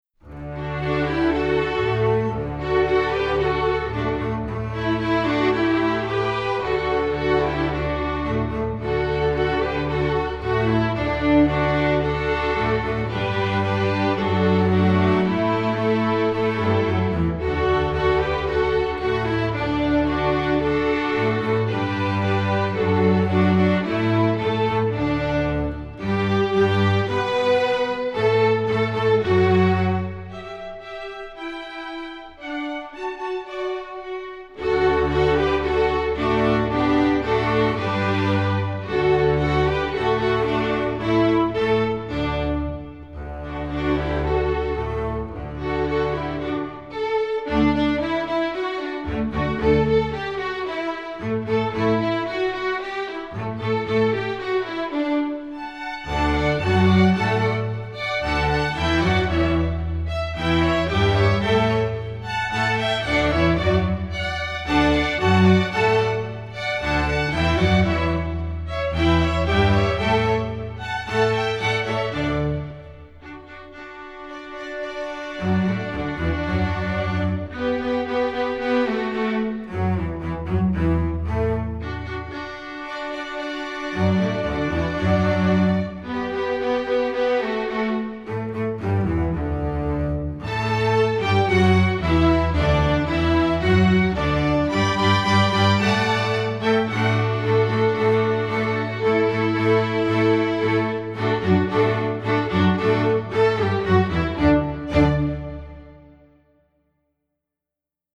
Composer: Traditional
Voicing: String Orchestra